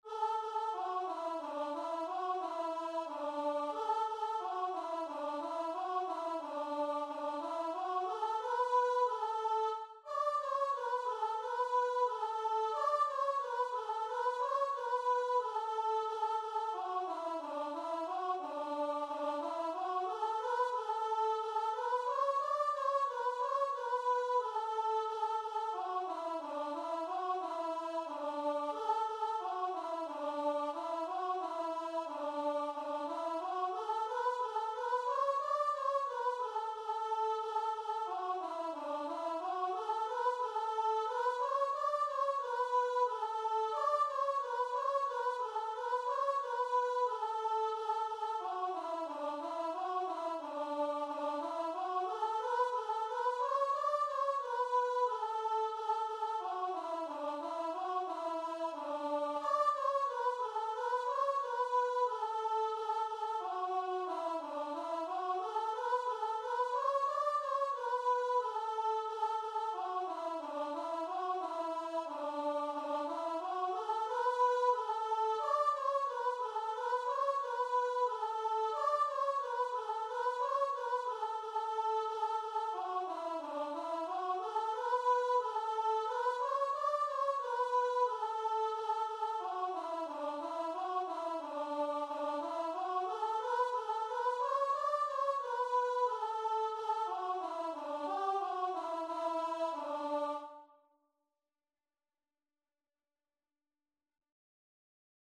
Free Sheet music for Voice
Traditional Music of unknown author.
D major (Sounding Pitch) (View more D major Music for Voice )
4/4 (View more 4/4 Music)
D5-D6
Voice  (View more Easy Voice Music)
Christian (View more Christian Voice Music)